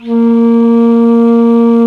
FLT ALTO F02.wav